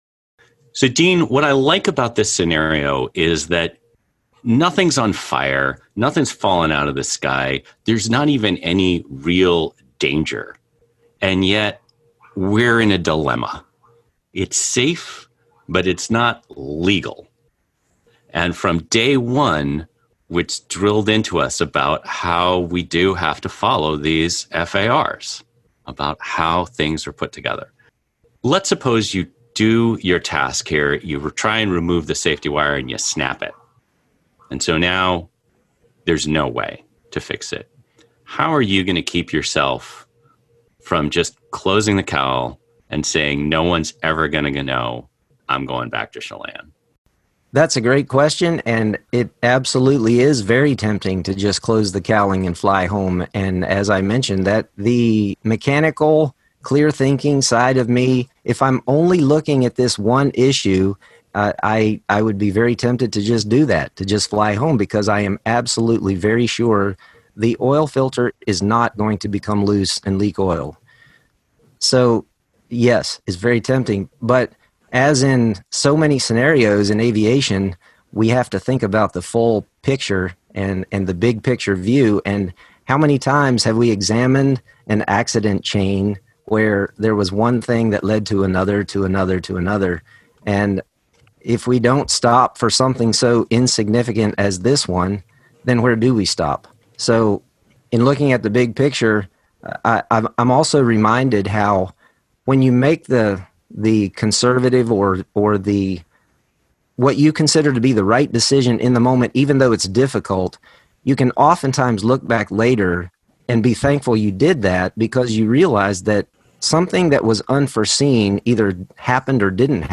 unsafety_wire_roundtable.mp3